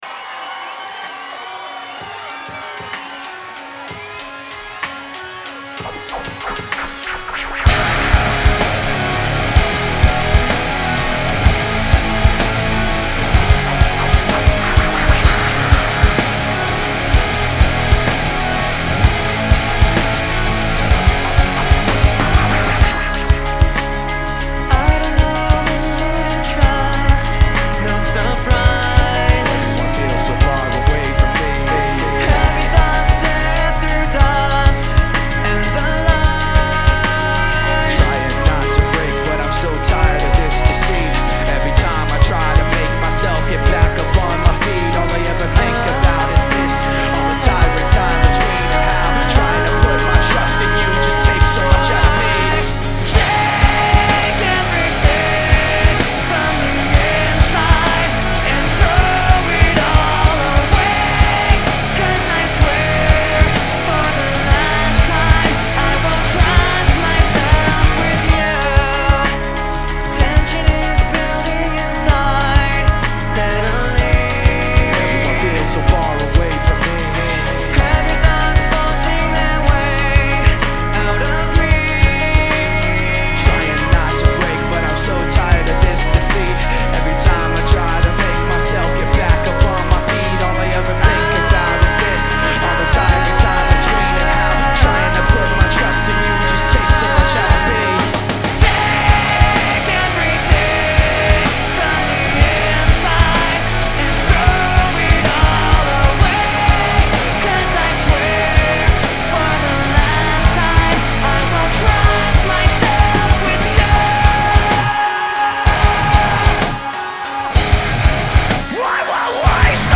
Genre : Rock, Alternative Rock